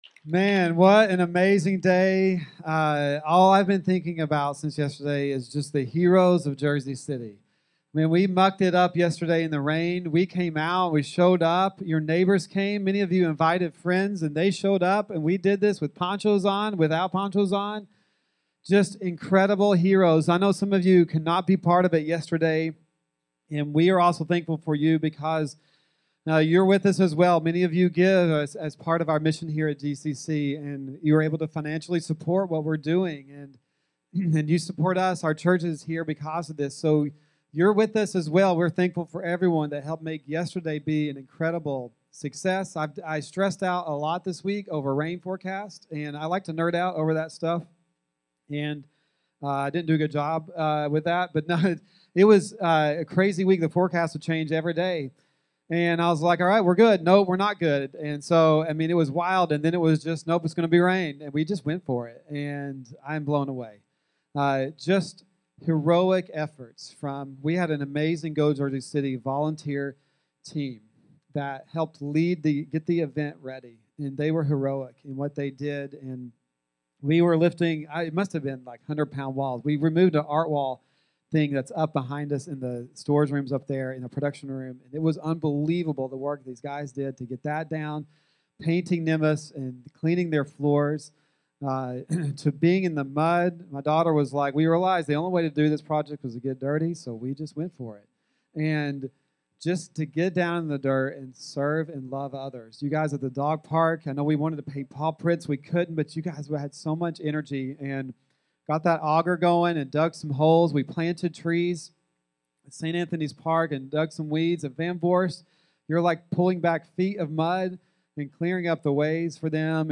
Check out our weekly podcast featuring conversations from our most recent Sunday series.